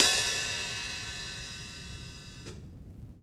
Index of /musicradar/Kit 9 - Vinyl
CYCdh_VinylK2-OpHat02.wav